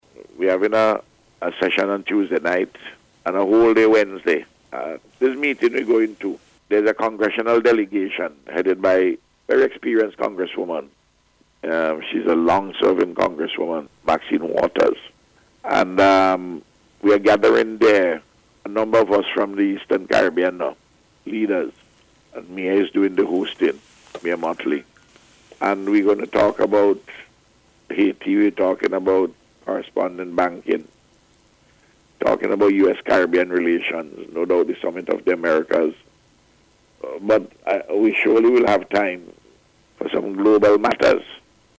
Speaking on NBC Radio this morning Prime Minister Gonsalves said a range of issues will be discussed at the meeting.